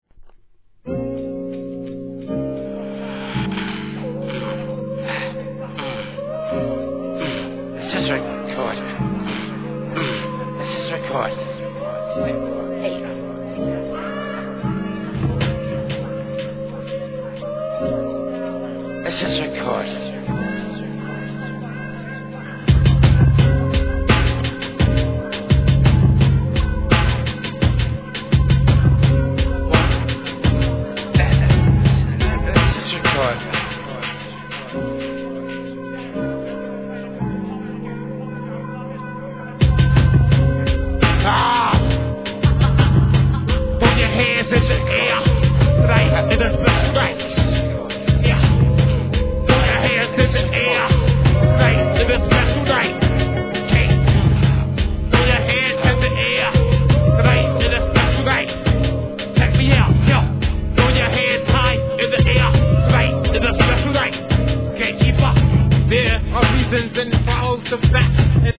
HIP HOP/R&B
期待通りのホラー色！！！